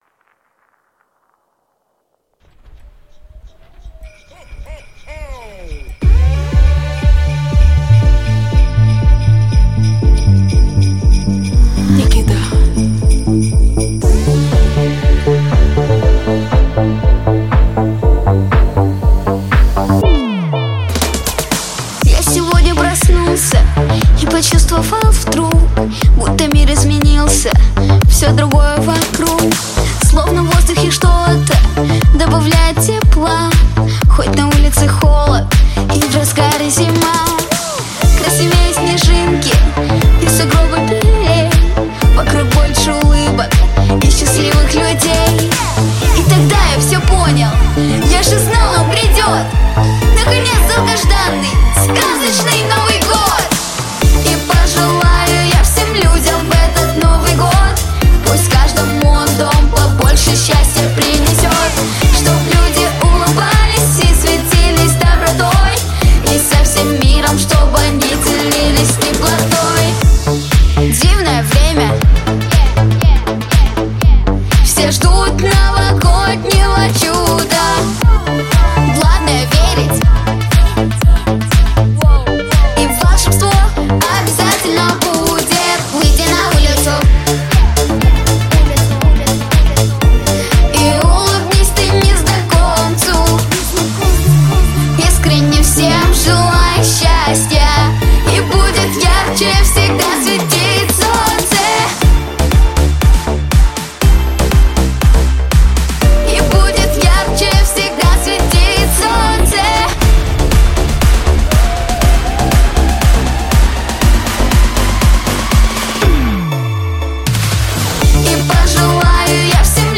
🎶 Детские песни / Песни на праздник / Песни на Новый год 🎄